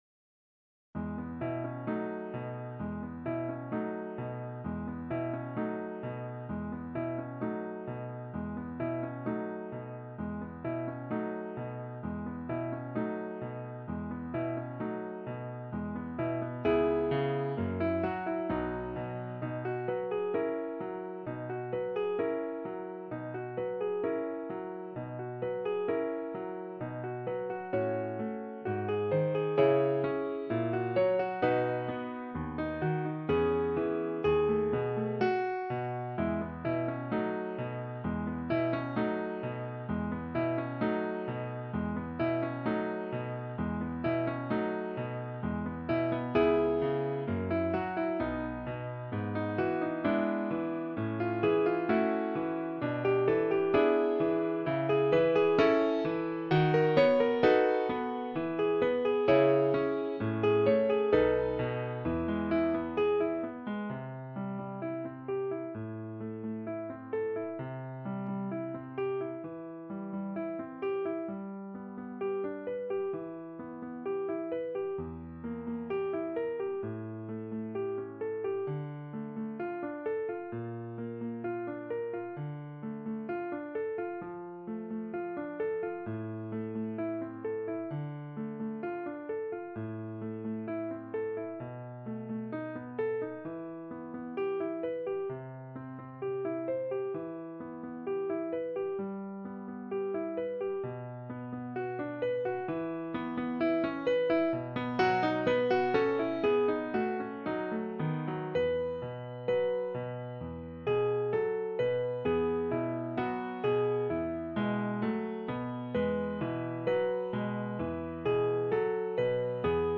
Piano accompaniment only to help you practice the solo instrumental part. Remember that the piano plays 2 bars of accompaniment before the main instrument starts.
faure-dolly-suite-1-berceuse-piano-accompaniment.mp3